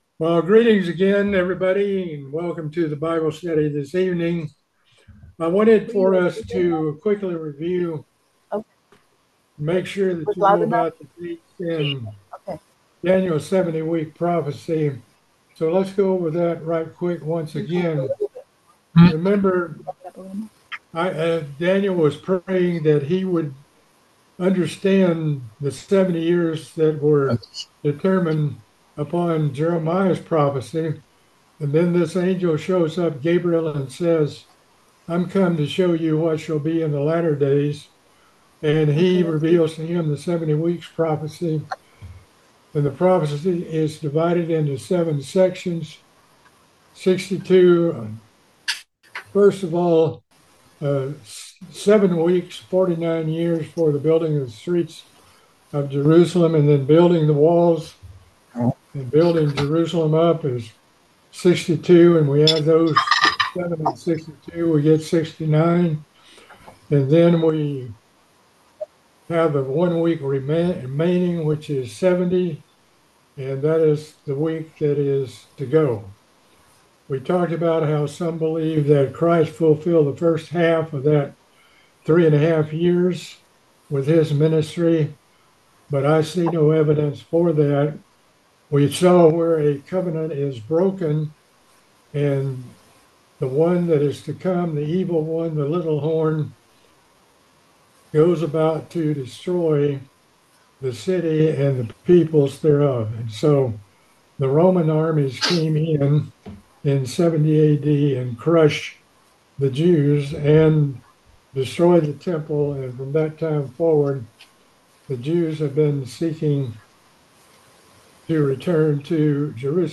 We continue our study in the book of Daniel. Continuing in chapter 10 and will go through chapter 11 and verse 20.